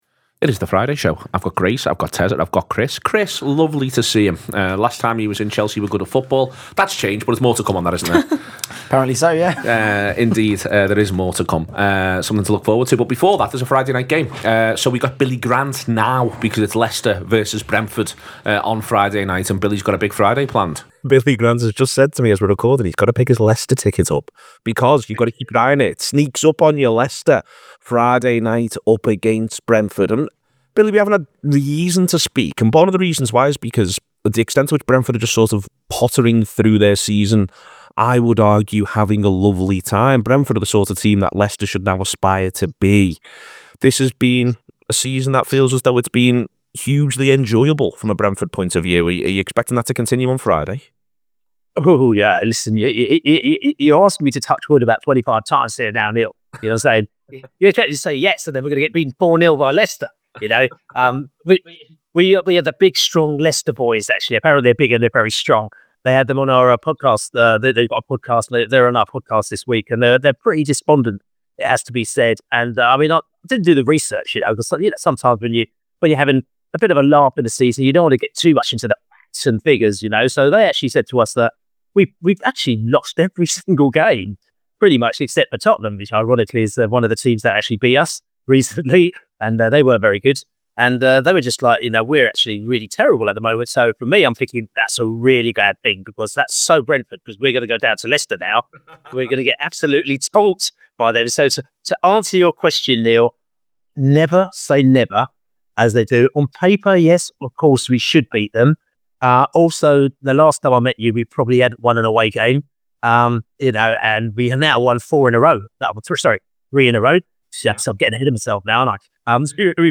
The Anfield Wrap’s preview show ahead of another massive weekend of football.